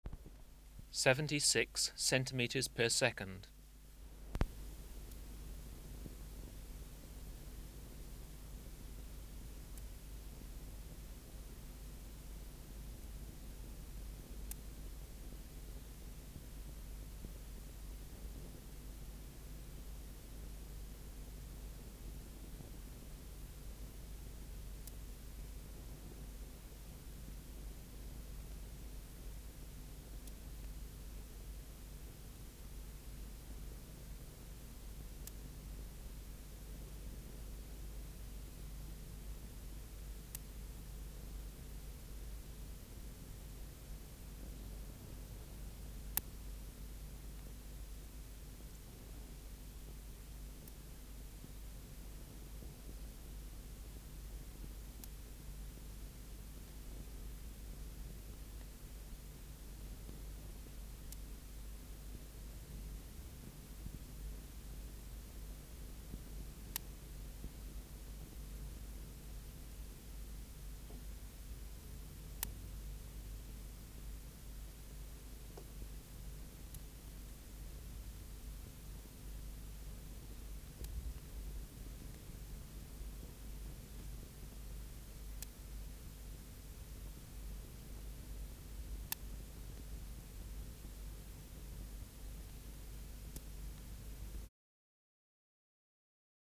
Species: Leptophyes albovittata
Recording Location: BMNH Acoustic Laboratory
Reference Signal: 1 kHz for 10 s
Substrate/Cage: small recording cage Biotic Factors / Experimental Conditions: Isolated male
Microphone & Power Supply: Sennheiser MKH 405 Distance from Subject (cm): 10